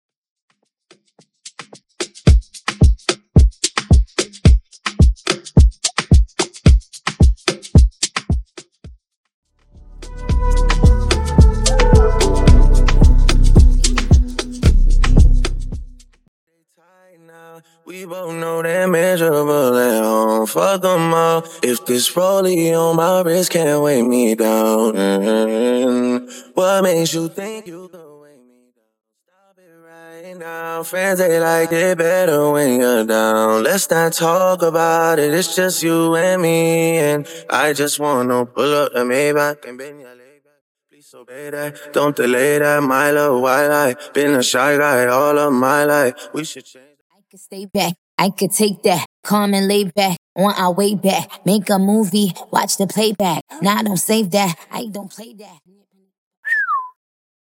Instrumental
Percussion & Drums Stem